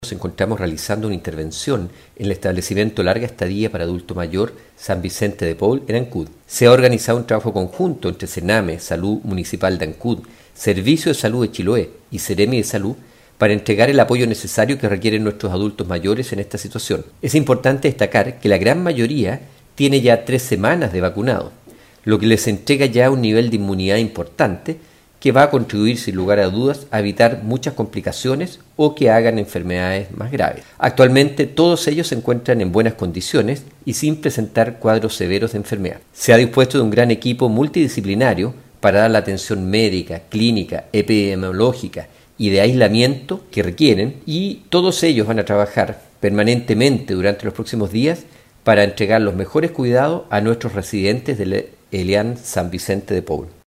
Por su parte, el seremi del ramo, Alejandro Caroca, informó sobre este hecho que se encuentran realizando una intervención en dicho hogar, para poder dar atención a los afectados y no permitir que los contagios continúen subiendo.